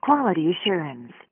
*⃣ Asterisk sound 'quality-assurance.wav'